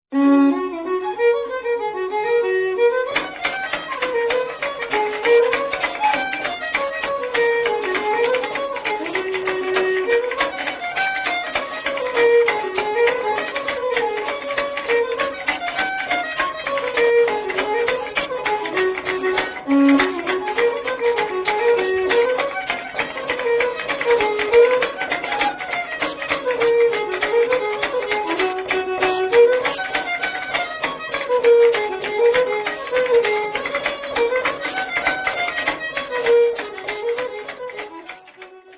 The dance music here is a fair selection of the types of tune commonly played in the pubs of the area: hornpipes, particularly Pigeon on the Gate and Sailor's Hornpipe, for stepdancing, polkas, schottisches and a fair smattering of song tunes.